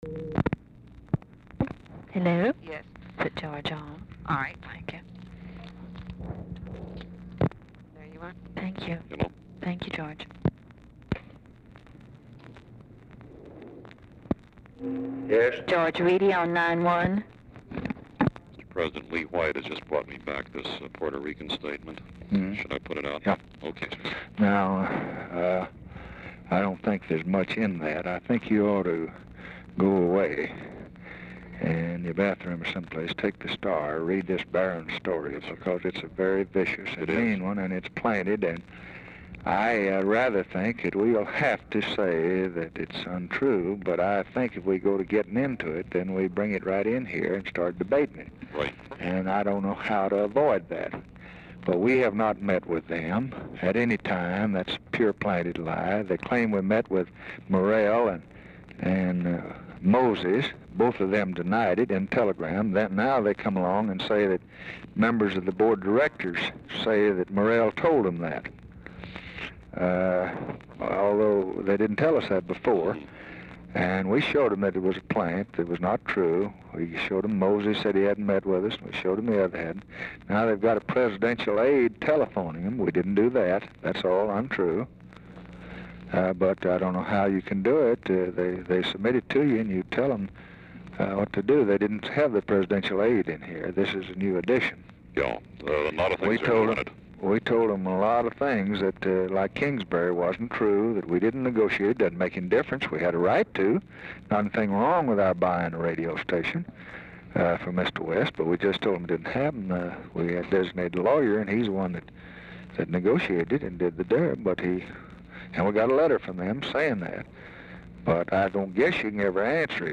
Telephone conversation # 3647, sound recording, LBJ and GEORGE REEDY, 6/9/1964, 12:31PM | Discover LBJ
Format Dictation belt
Location Of Speaker 1 Oval Office or unknown location
Specific Item Type Telephone conversation